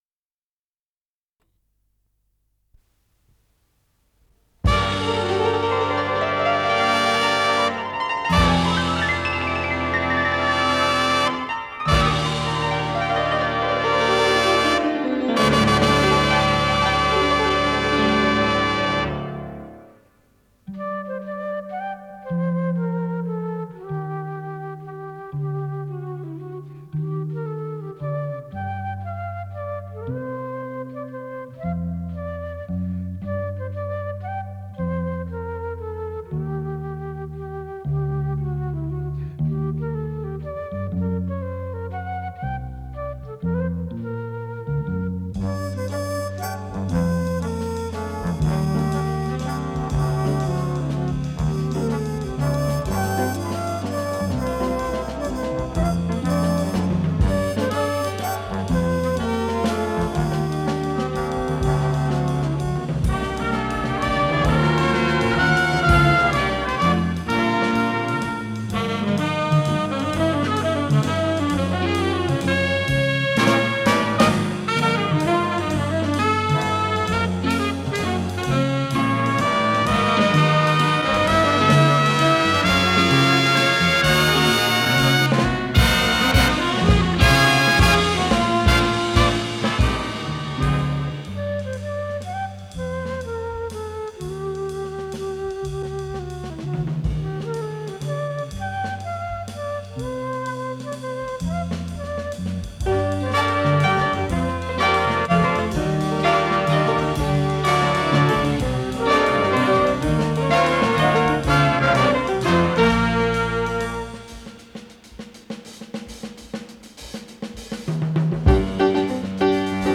с профессиональной магнитной ленты
Соло на флейте
Соло на фортепиано
ВариантДубль моно